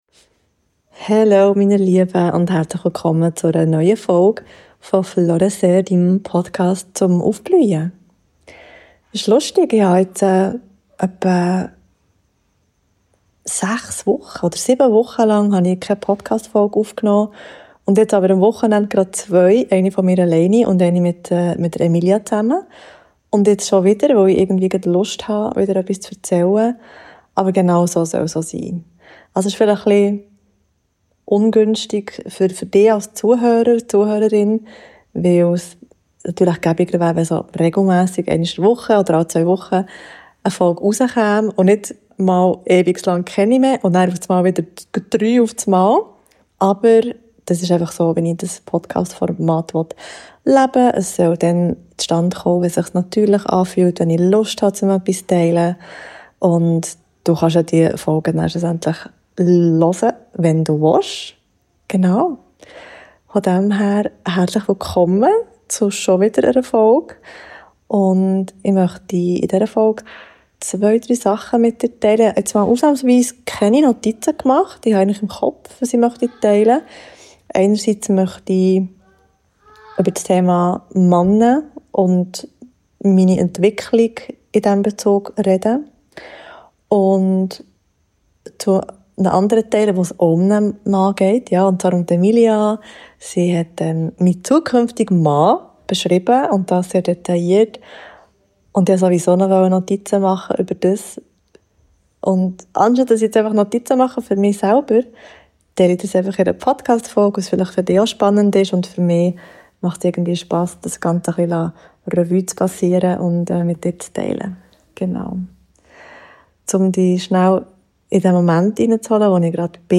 Das Kindergeschrei ist nur in den ersten 5 Minuten – versprochen Viel Spass beim Zuhören!